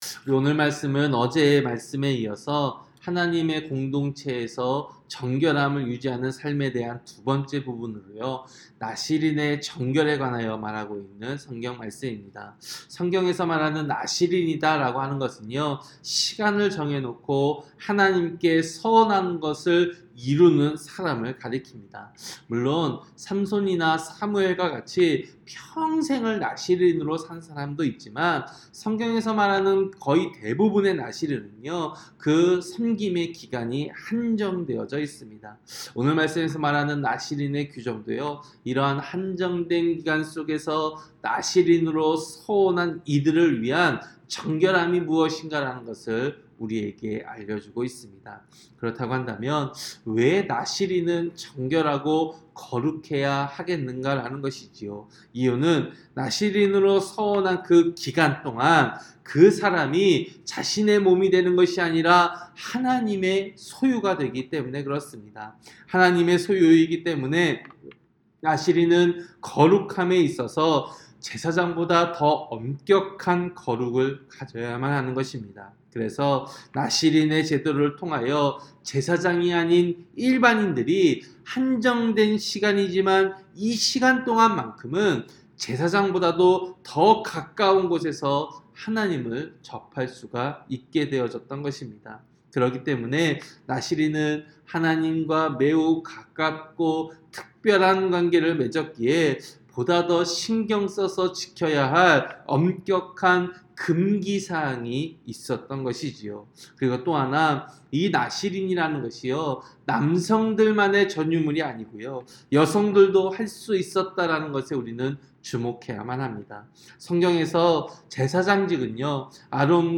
새벽설교-민수기 6장